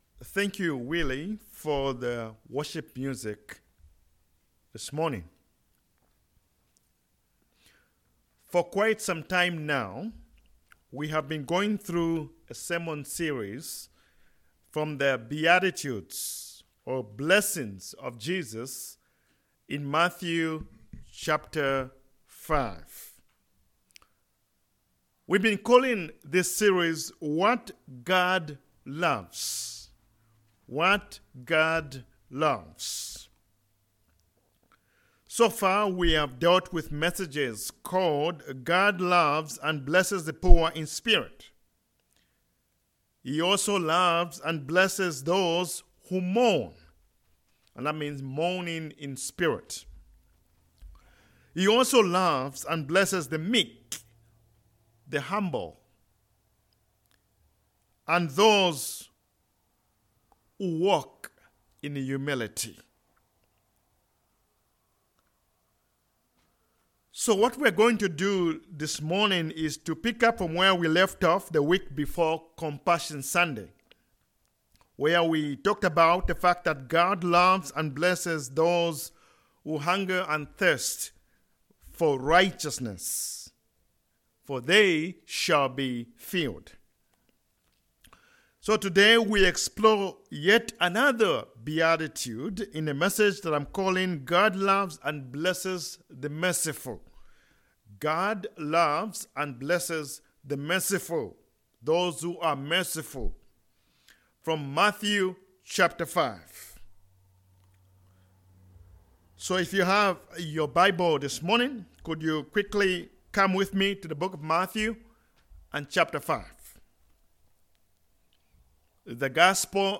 God Loves Passage: Matthew 5: 7 Service Type: Sermons « Count Your Blessings!